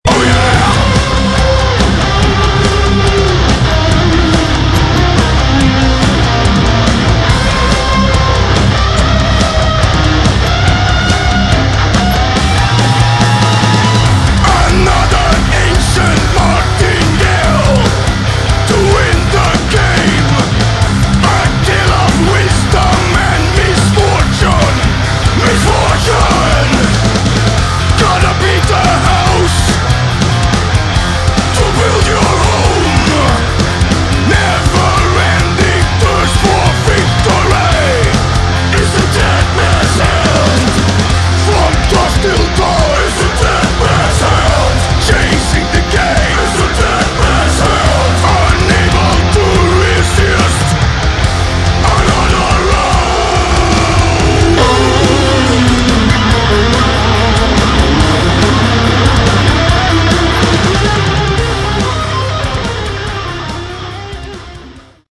Category: Melodic Metal
vocals
guitar
bass
drums
This thrash.